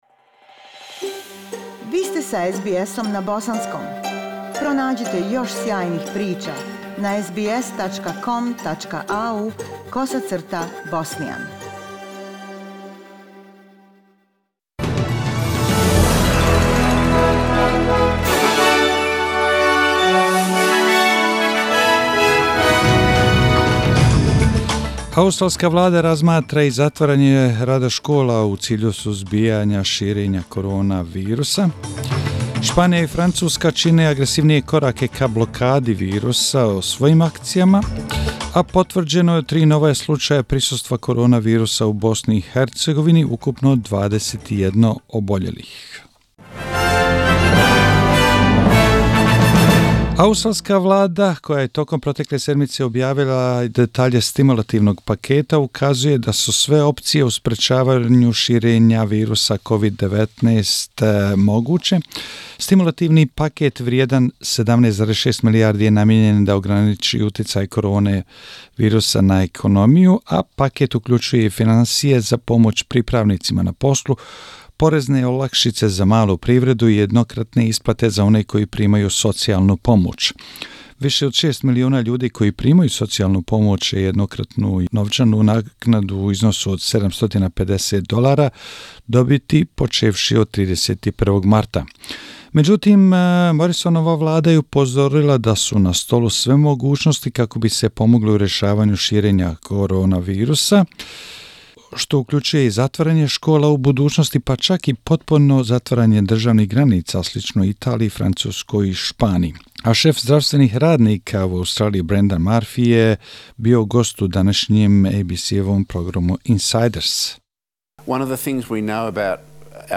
SBS Bosnian News March 15 / Vijesti za 15. mart